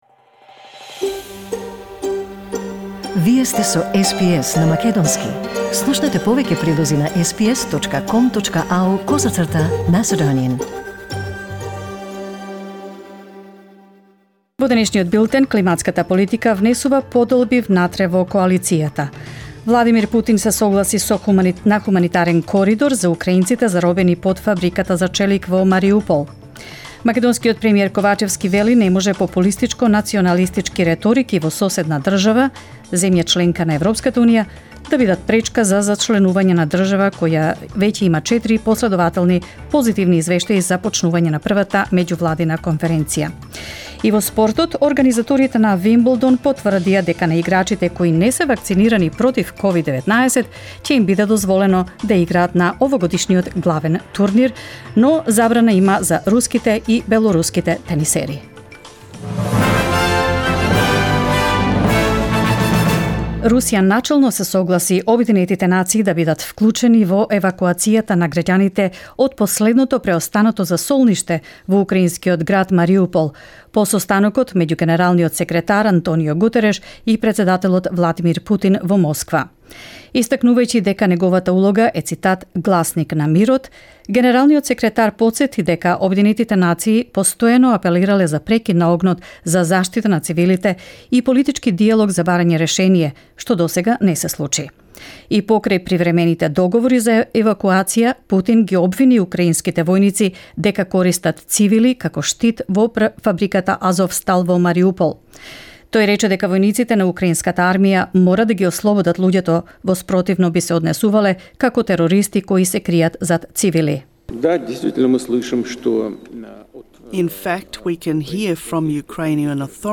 SBS News in Macedonian 27 April 2022